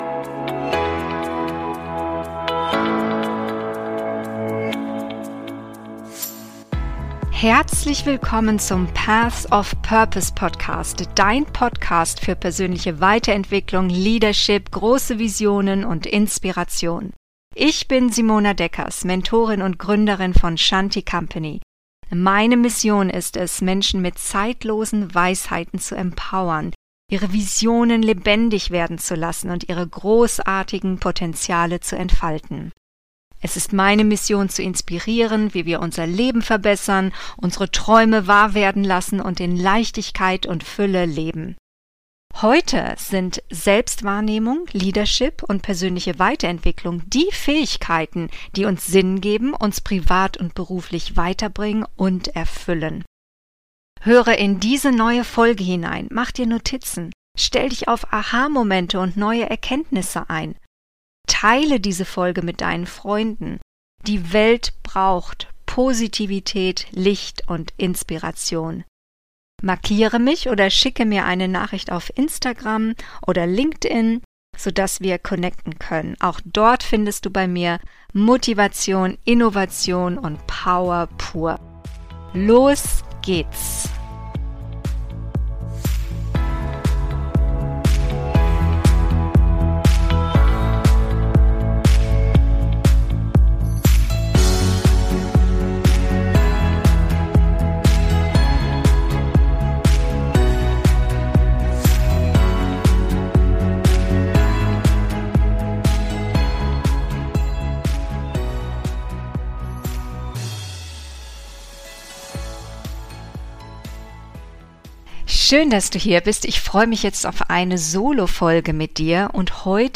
Leadership für gute Beziehungen - Solofolge